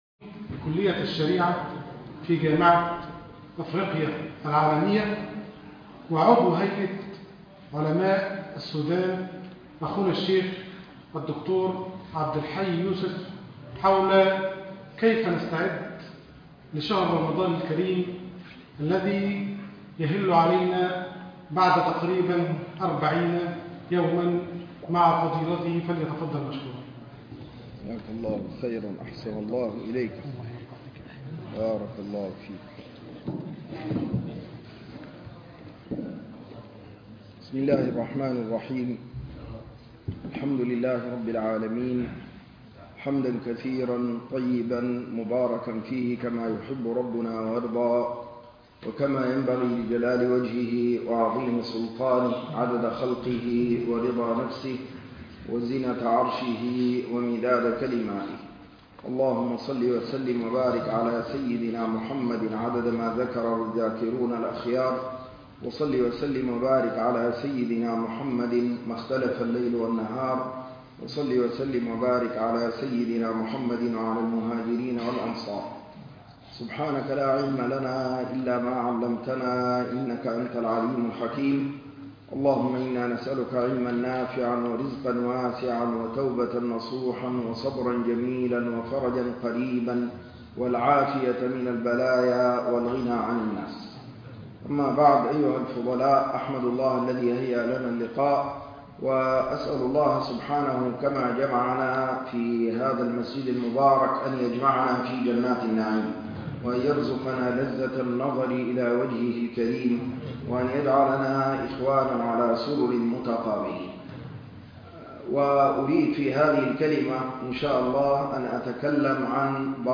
كيف نستقبل رمضان؟ - البرنامج الدعوي بمدينة بيرمنغهام - بريطانيا